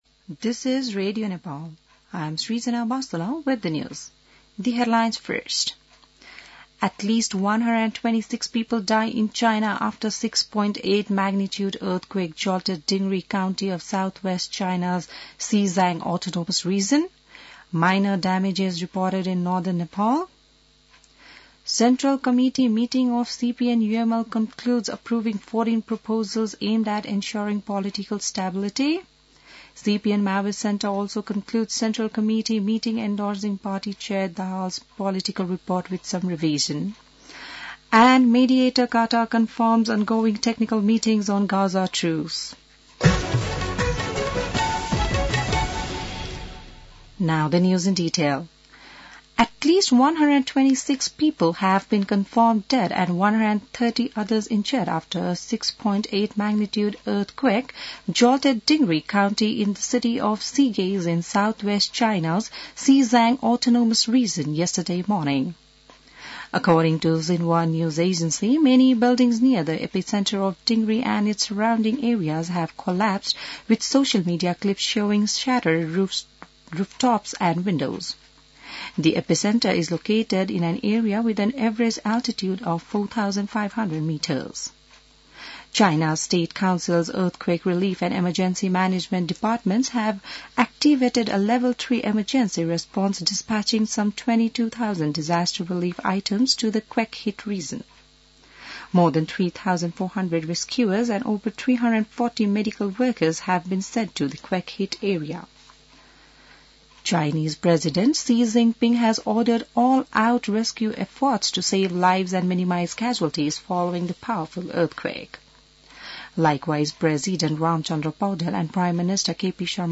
बिहान ८ बजेको अङ्ग्रेजी समाचार : २५ पुष , २०८१